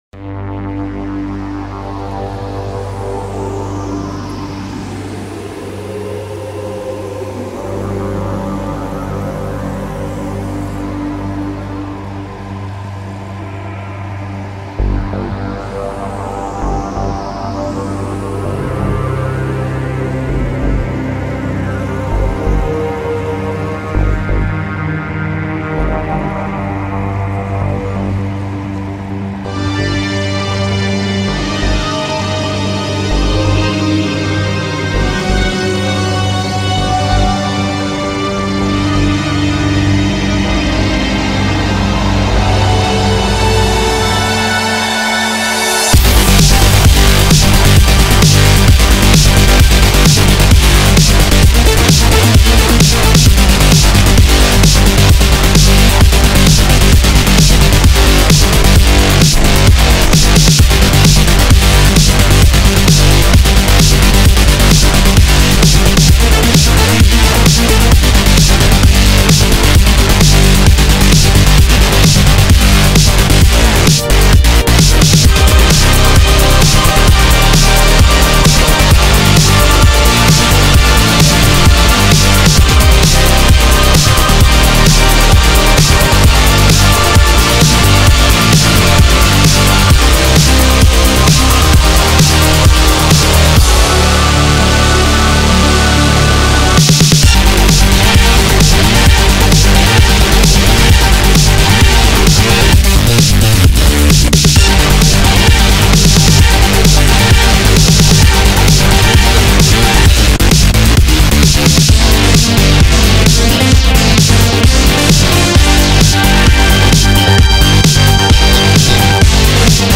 Dark Synthwave AMV